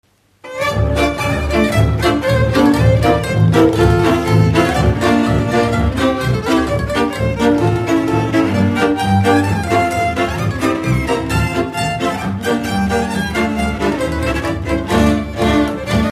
Dallampélda: Hangszeres felvétel
Alföld - Pest-Pilis-Solt-Kiskun vm. - Bogyiszló
hegedű
brácsa
tambura (prím)
tamburabrácsa
bőgő
Műfaj: Ugrós
Stílus: 6. Duda-kanász mulattató stílus